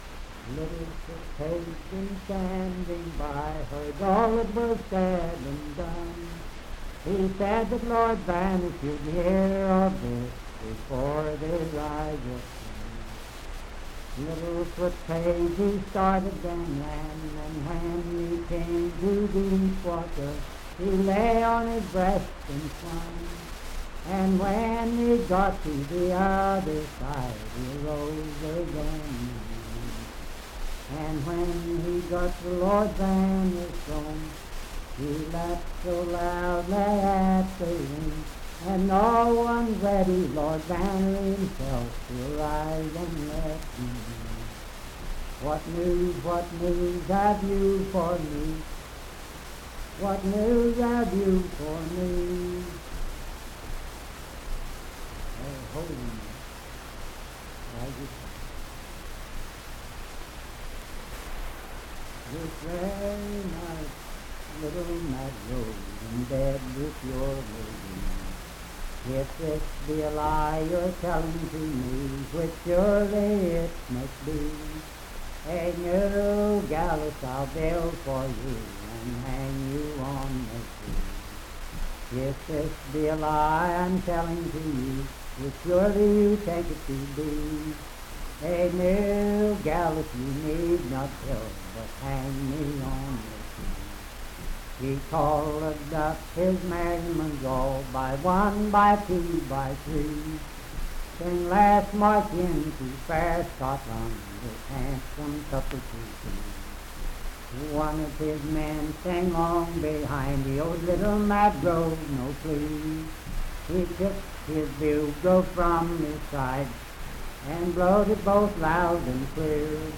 Unaccompanied vocal music and folktales
Voice (sung)
Wood County (W. Va.), Parkersburg (W. Va.)